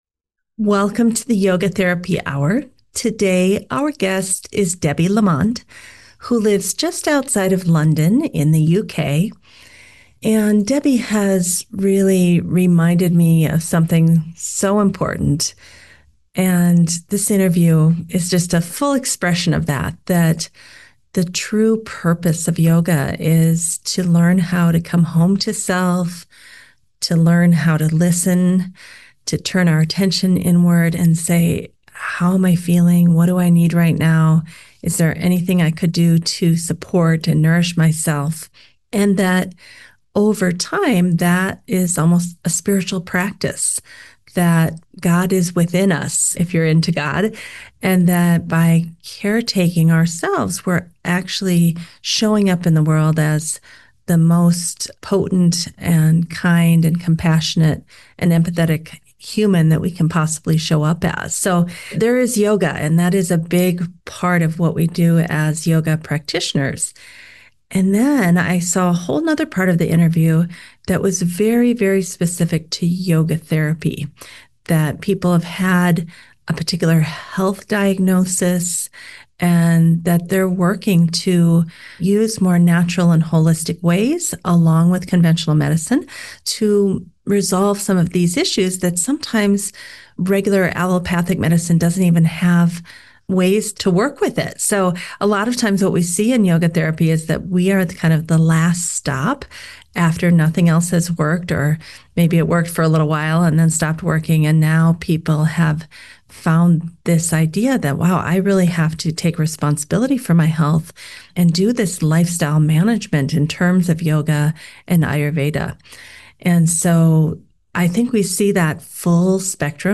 This conversation feels like tea with a wise friend—practical, hopeful, and grounded in ahiṃsā, svādhyāya, and the steady courage of śraddhā.